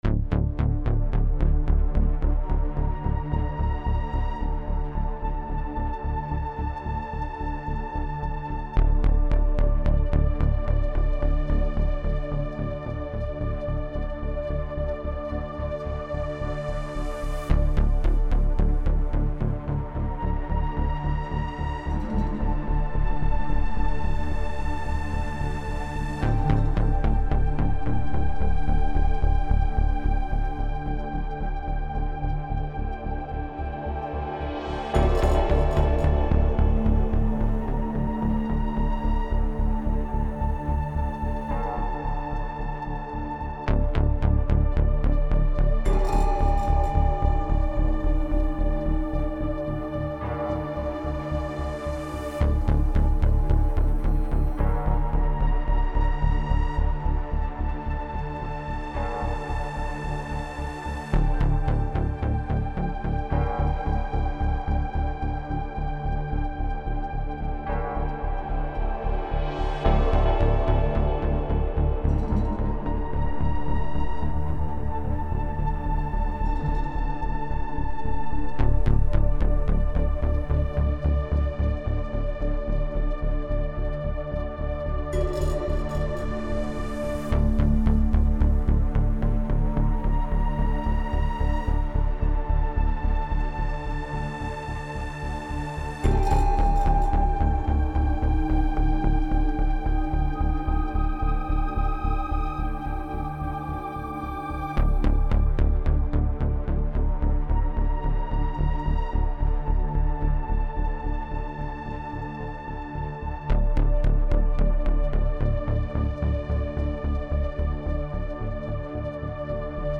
Some more atmospheric ambience.